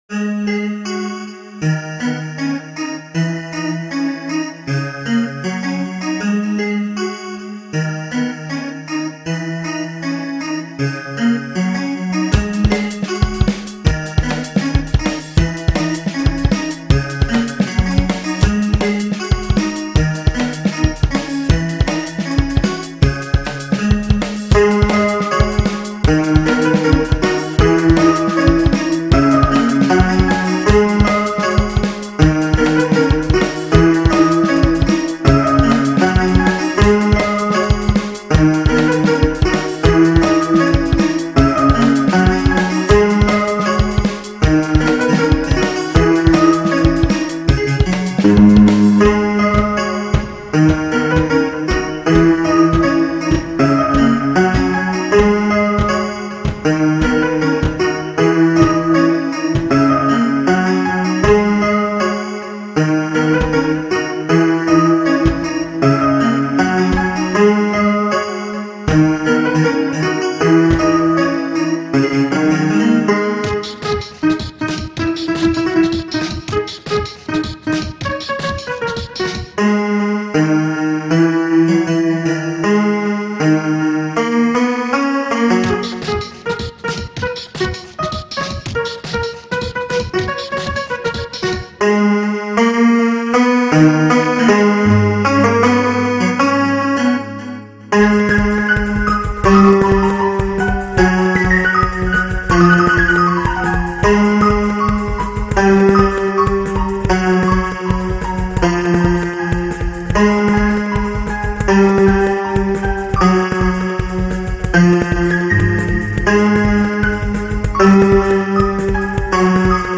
Why do I even bother adding drums to this xD.